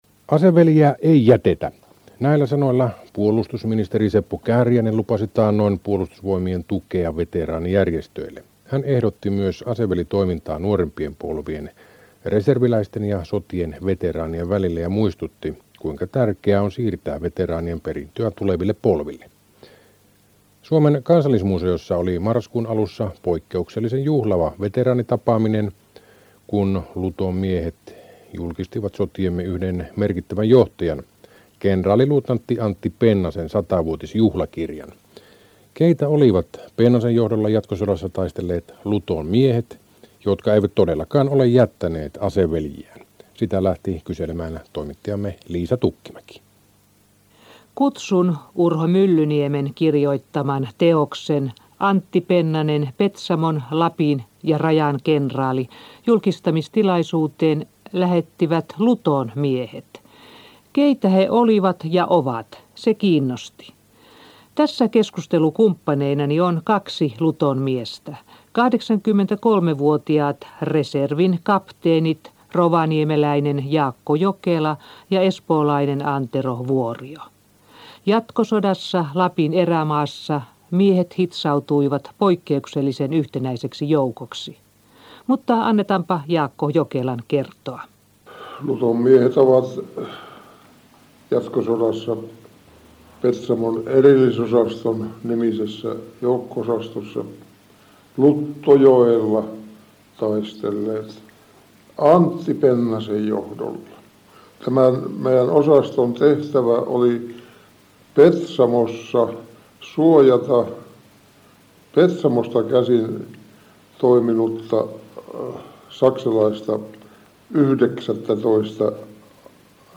Tänään Iltapäivällä haastattelu 6.12.2003